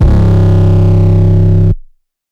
REDD 808 (8).wav